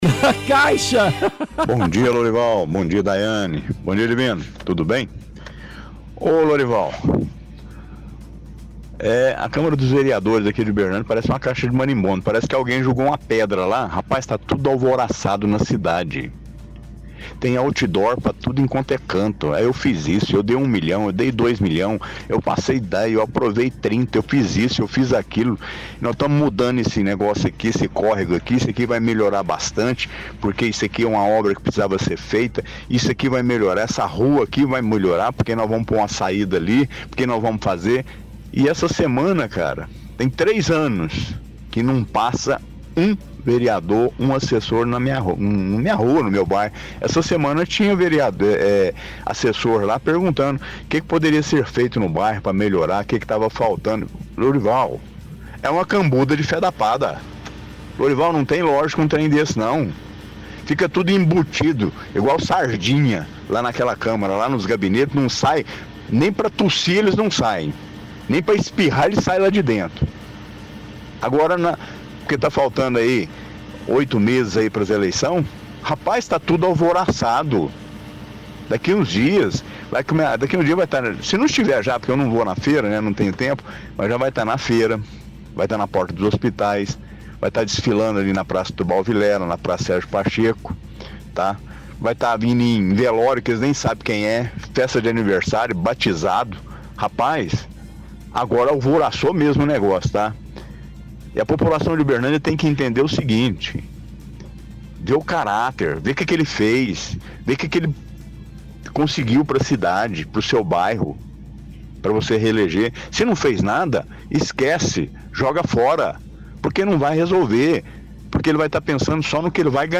Home / Rádio / Pinga fogo – Vereadores